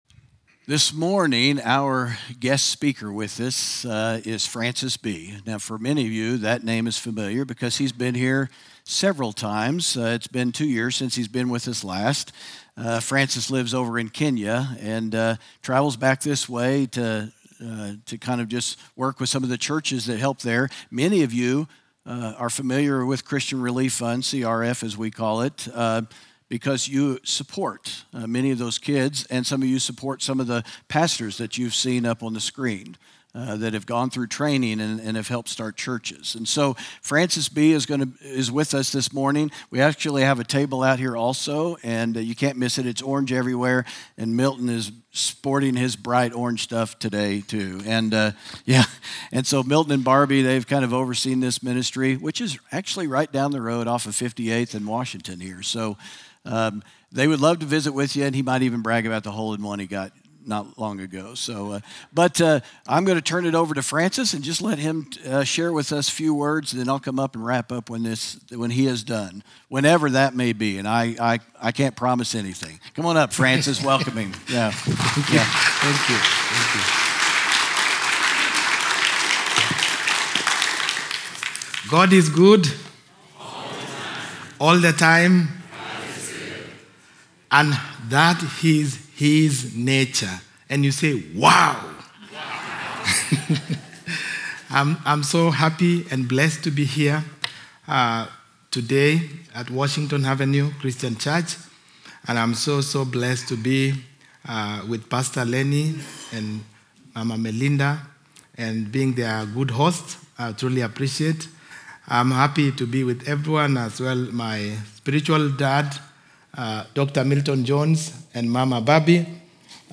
sermon audio 0921.mp3